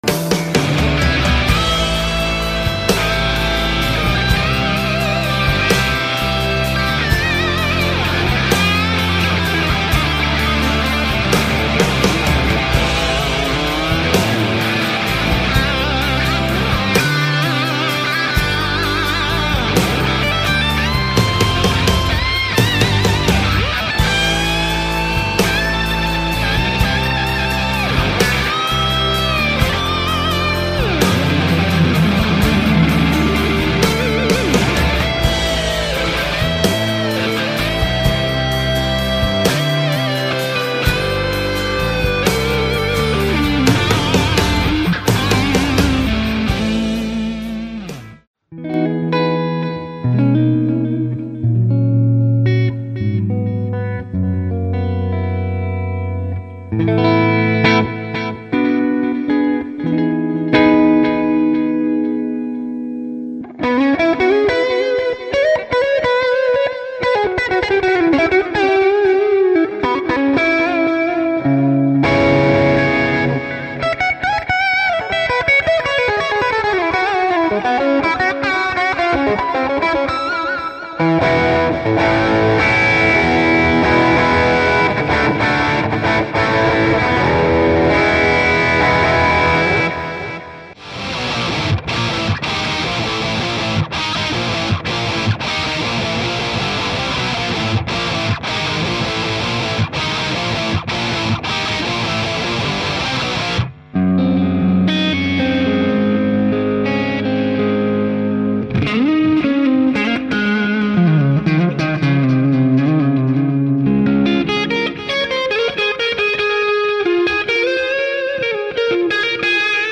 Sonicake Pocket Master Multi Effects (Demo In Details) – Selectafex
Pocket Master is a compact multi-effects processor with potent circuit design and high quality digital effects.
✪ 20 legendary guitar/bass/acoustic amp types
✪ 99 high quality drum patterns